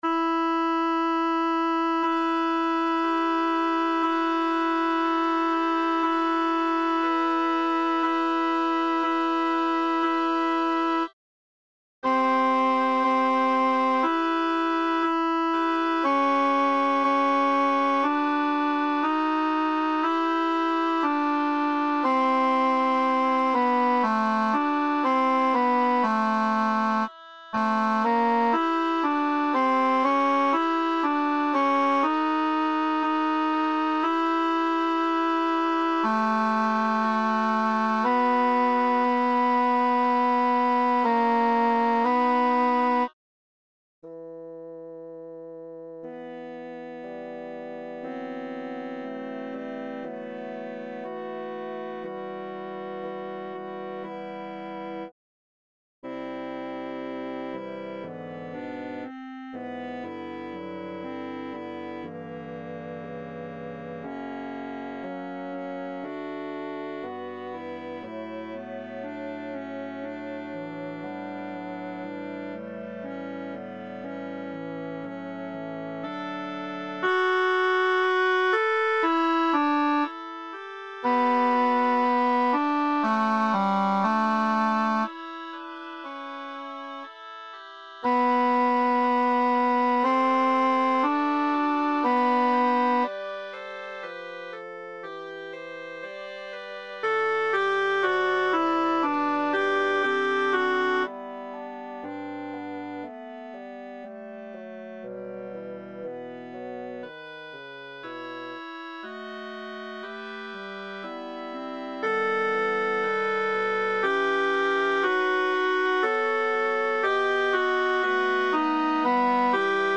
Kyrie à 8 voix mp3
Les fichiers mp3 (de grandes dimensions) sont faits à partir des fichiers midi (de très petites dimensions), avec la voix sélectionnée accentuée.